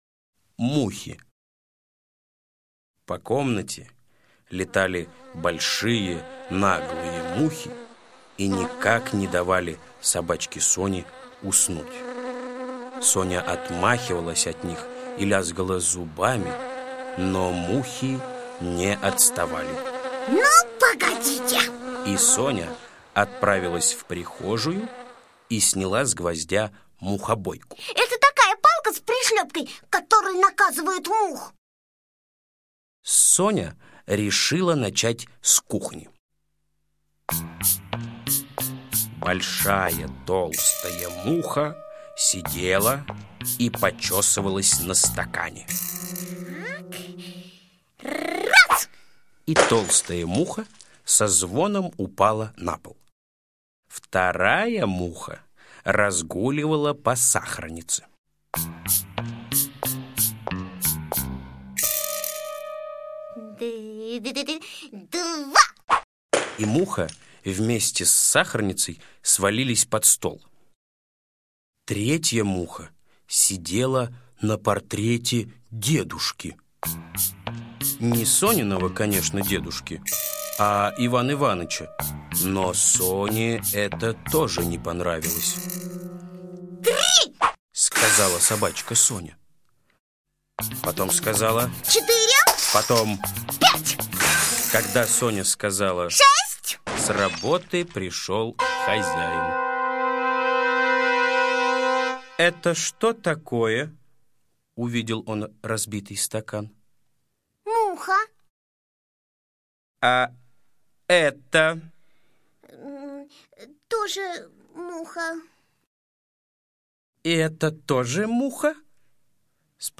Мухи - аудиосказка Усачева А.А. Собачка Соня очень не любила мух, они мешали ей спать.